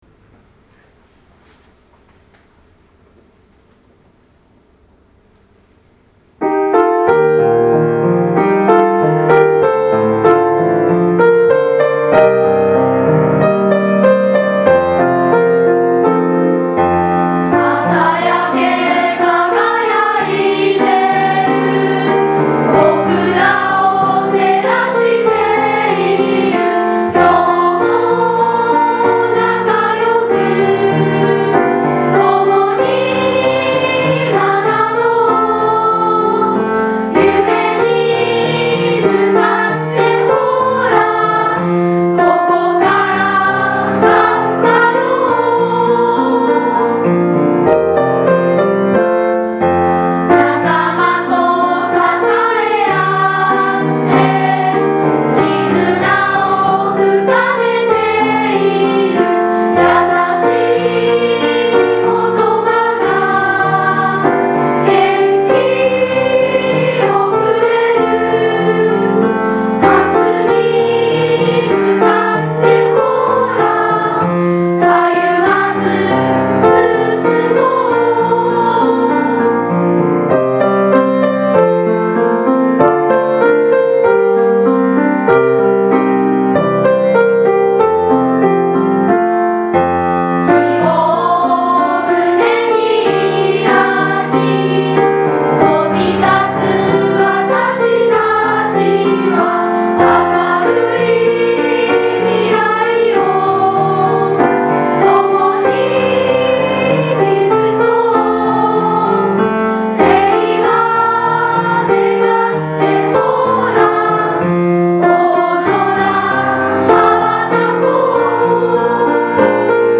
学園歌